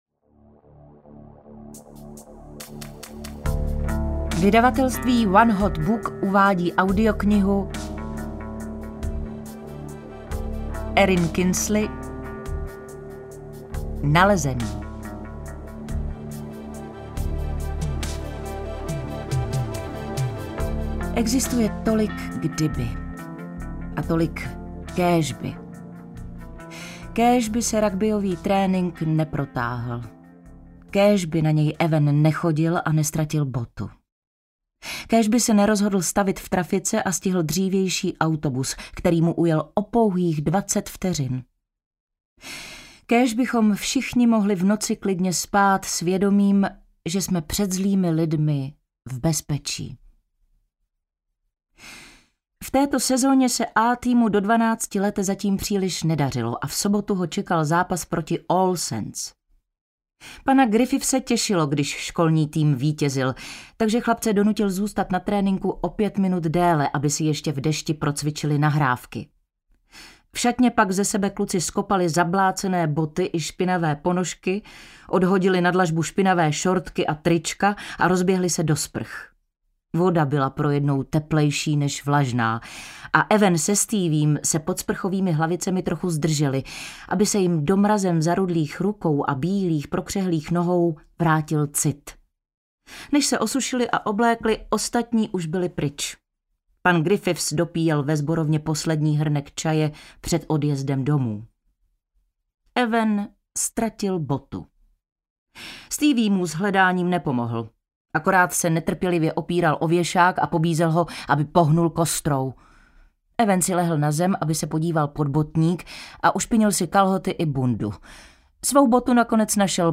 Nalezený audiokniha
Ukázka z knihy
• InterpretKlára Cibulková